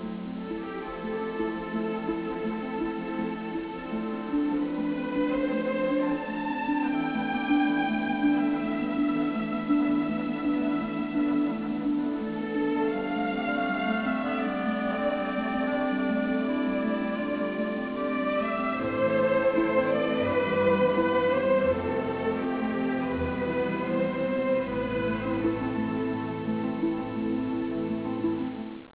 Melodica, ma ricca di possenti marce romane
Original track music